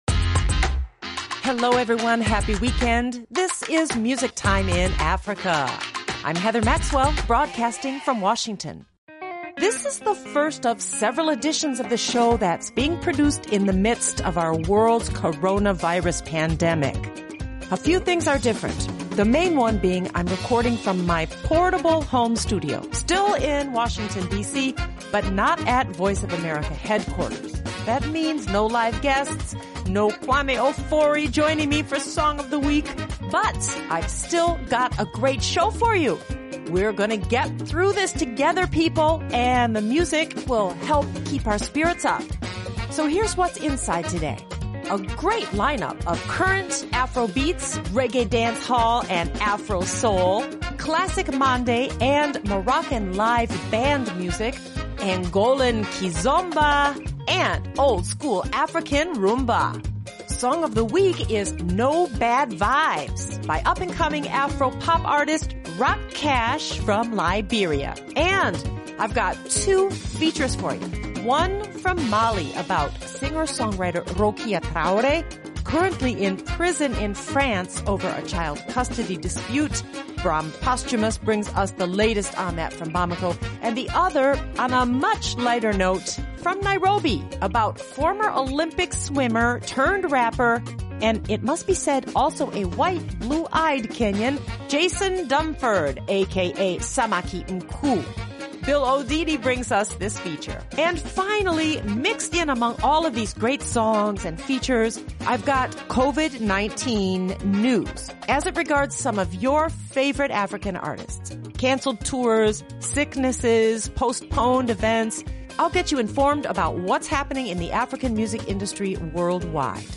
Find out answers to all of these questions inside today's episode all the while, enjoying awesome pan-African music.